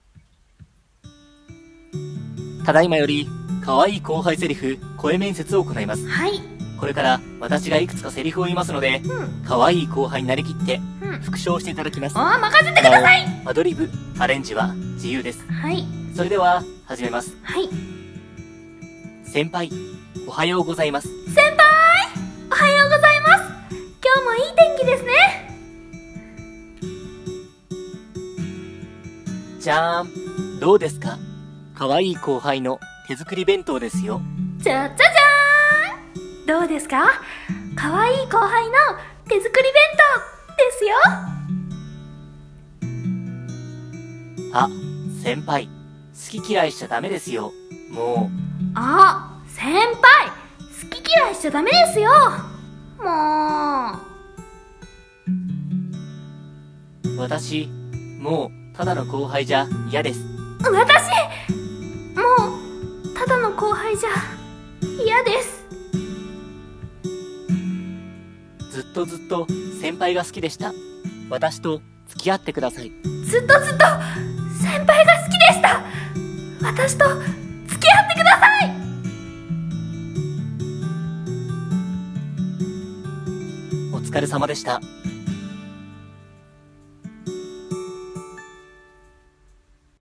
可愛い後輩セリフ声面接